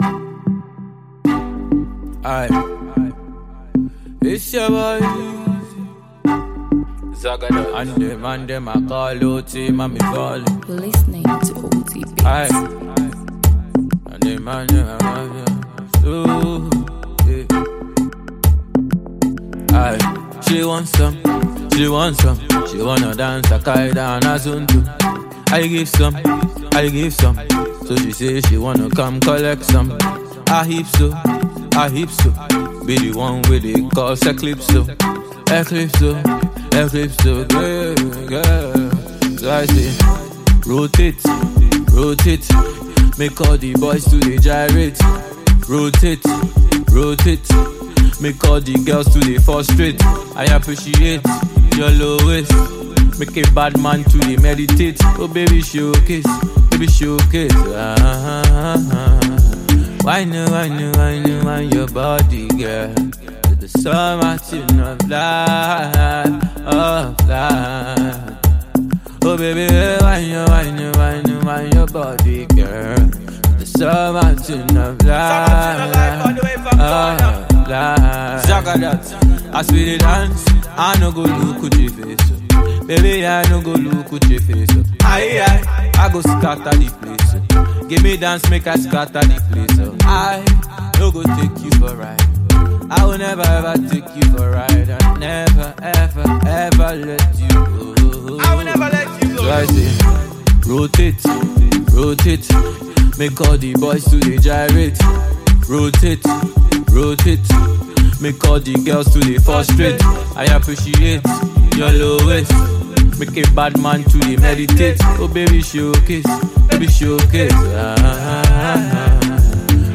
a freestyle.